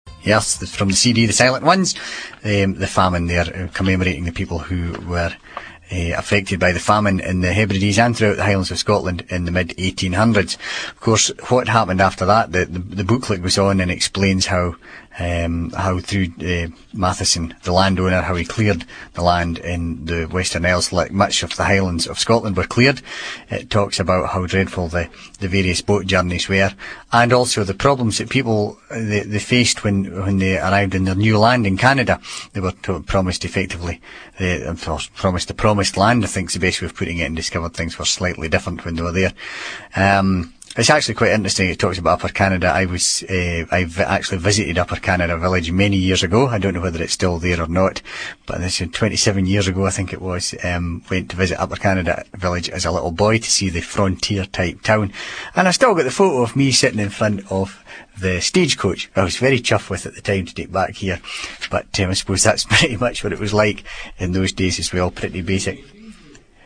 Scottish English, broad
Vowel length is not distinctive and is determined by the phonological environment in which vowels occur. Like Ulster English, it has a high mid vowel in the GOOSE lexical set and a retroflex [ɻ]. The vowel of the TRAP lexical set is a long low vowel, i.e. trap is [tra:p].
Scotland_Broad.wav